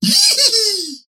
Still thinking about the Dynamo giggle for some rocket boot item not in the game (yet or was?). His whimsy never fails to make me tear up.
dynamo_use_rocket_boots_03_02.mp3